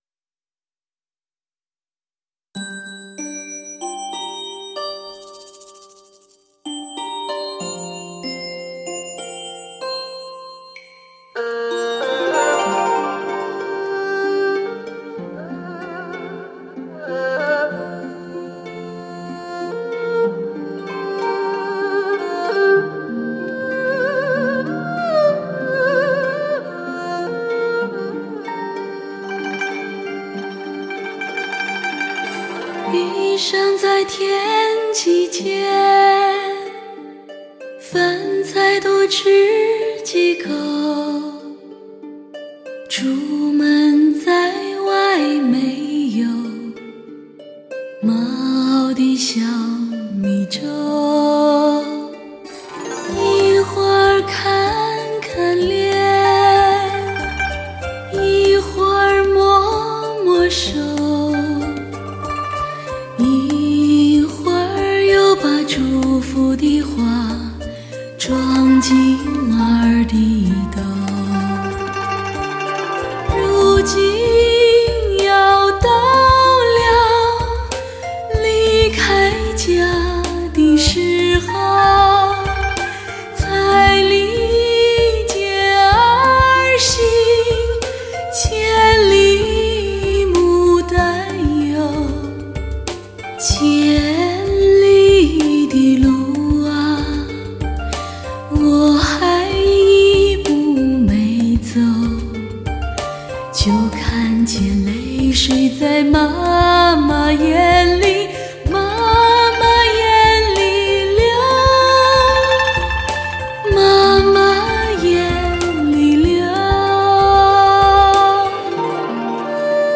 以近乎完美的唱功和无可挑剔的情感传递，势必将这股“草原风”与“民族风”传播的更广泛更久远。